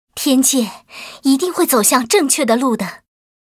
文件 文件历史 文件用途 全域文件用途 Erze_amb_05.ogg （Ogg Vorbis声音文件，长度3.4秒，92 kbps，文件大小：39 KB） 源地址:地下城与勇士游戏语音 文件历史 点击某个日期/时间查看对应时刻的文件。